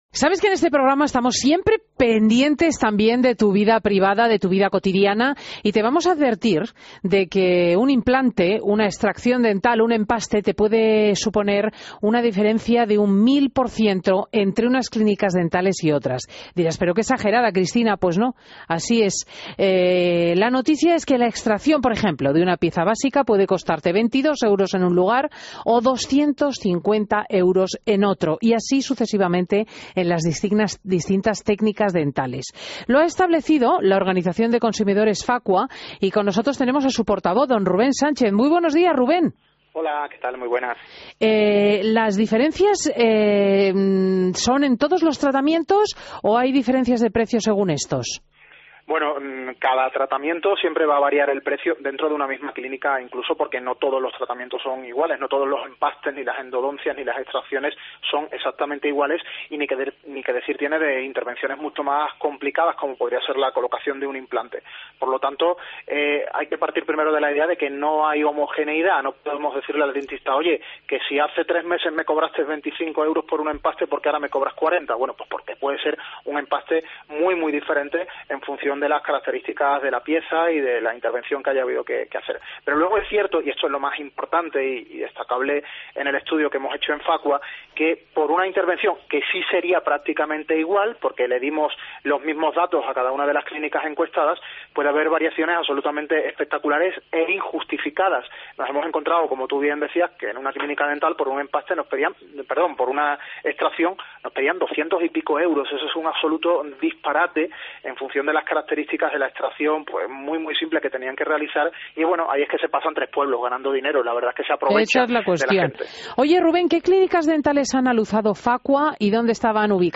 AUDIO: Entrevista
Entrevistas en Fin de Semana